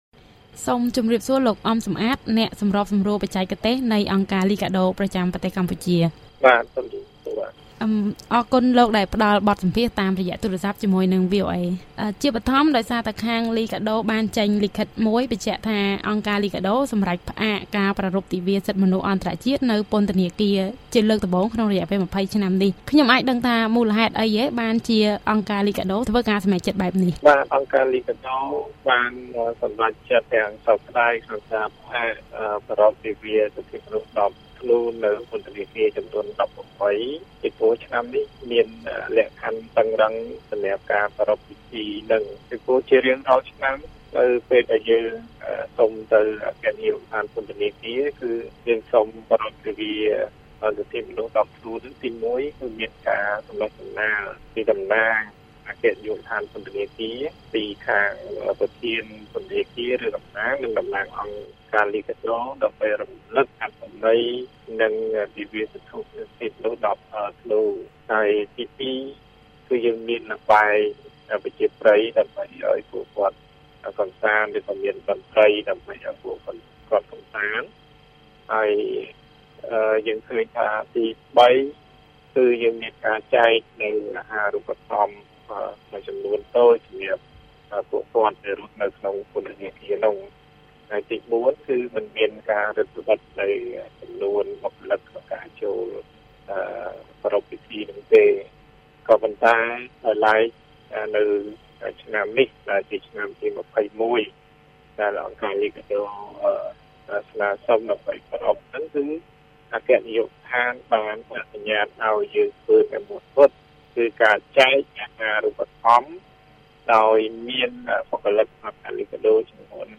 បទសម្ភាសន៍ VOA៖ អង្គការលីកាដូផ្អាកការប្រារព្វទិវាសិទ្ធិមនុស្សនៅពន្ធនាគារដោយច្បាប់អង្គការ